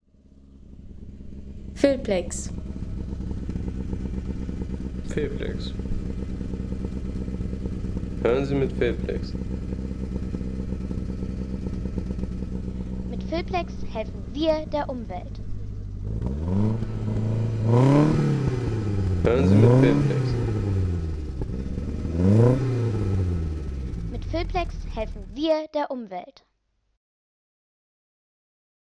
Lotus Elan S2 - 1965
Lotus Elan S2 – Der Sportliche Klassiker von 1965.